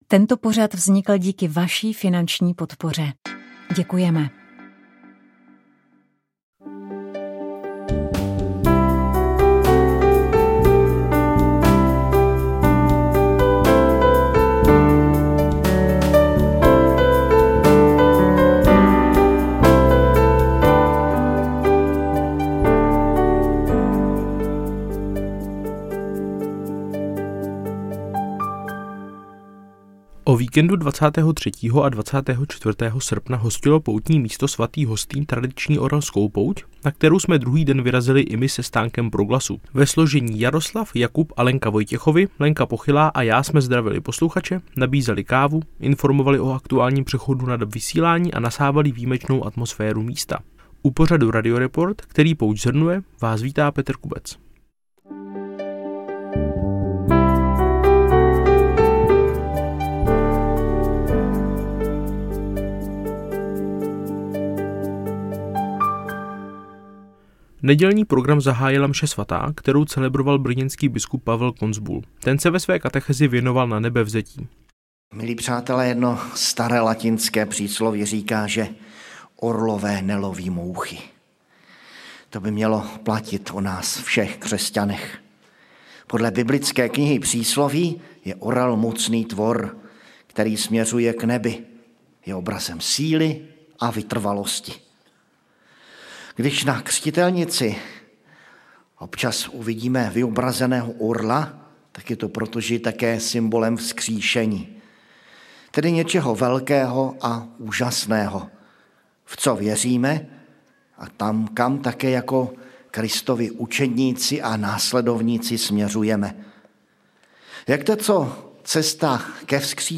V pořadu zazní rovněž ukázky lidové hudby, která se k různým dnům tohoto období vázala.